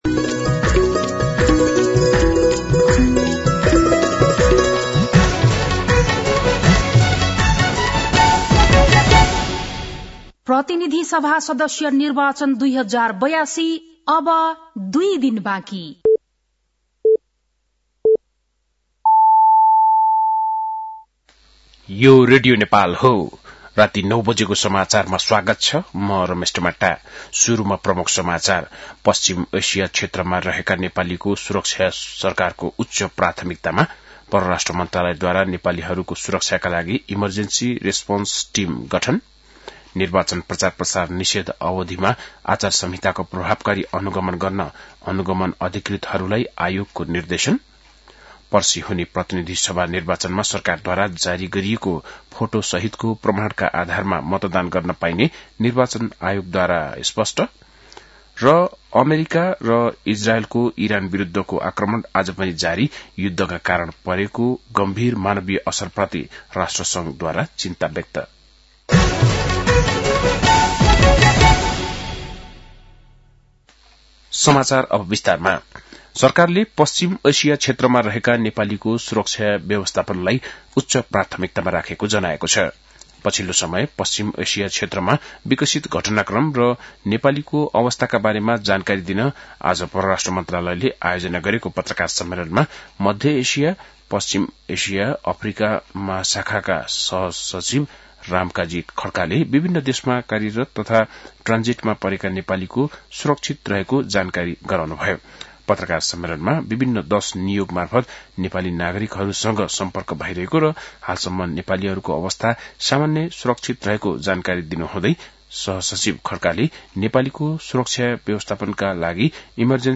बेलुकी ९ बजेको नेपाली समाचार : १९ फागुन , २०८२